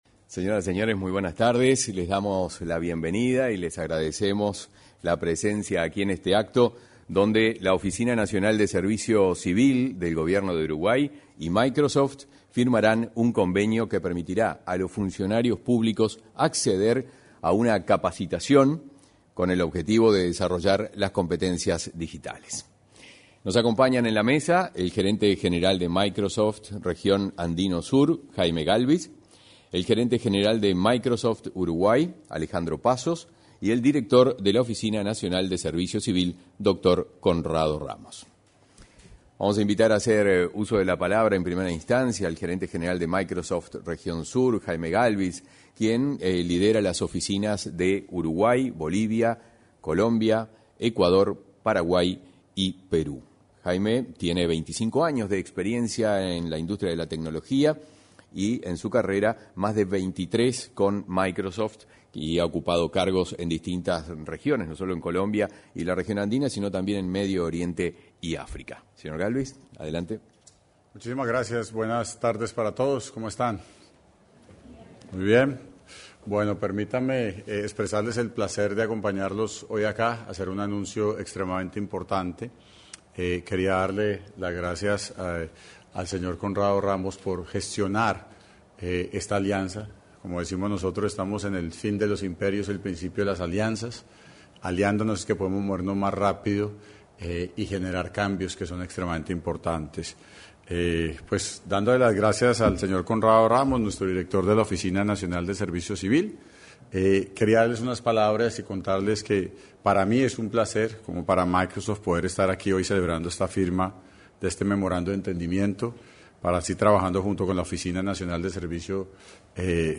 En el salón de actos se concretó la firma del acuerdo para el desarrollo de competencias digitales